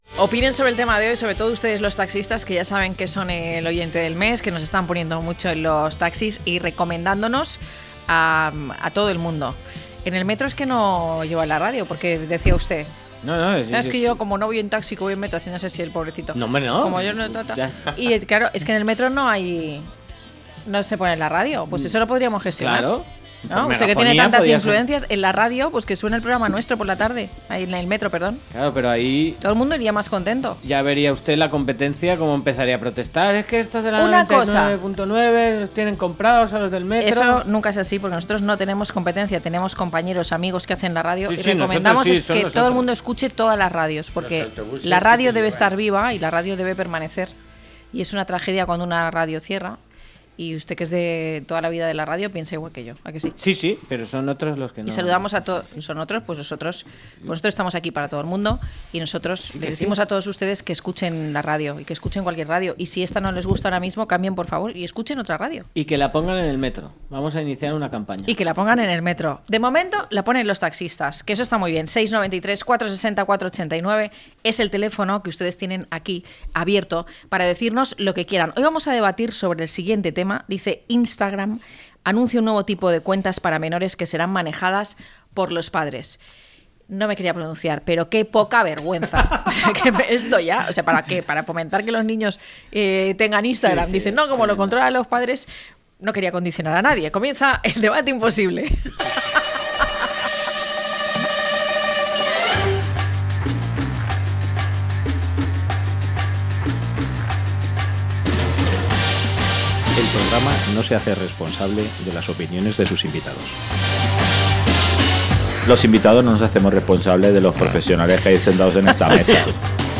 Nuestros invitados cuentan sus experiencias en los diferentes servicios y exponen las ventajas y desventajas de ambos tipos de transporte, así como el punto de vista de un taxista que interviene en el debate a partir de una llamada.